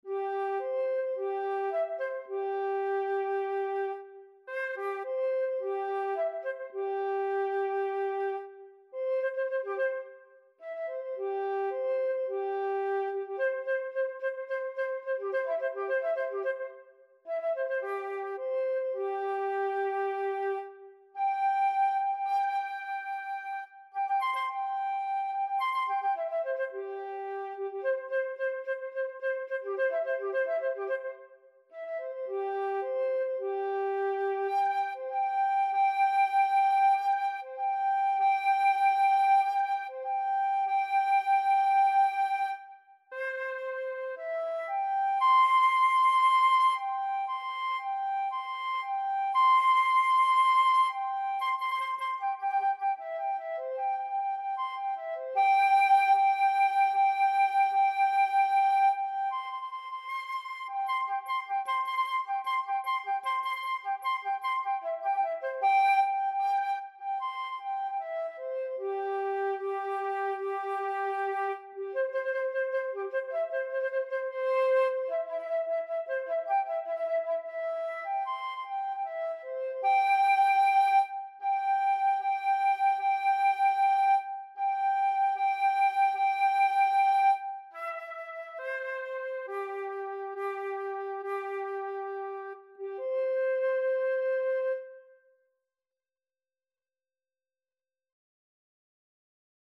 Classical Trad. Last Post and Reveille (Cavalry) Flute version
C major (Sounding Pitch) (View more C major Music for Flute )
Moderately fast ( = c.108)
2/4 (View more 2/4 Music)
Flute  (View more Easy Flute Music)
Classical (View more Classical Flute Music)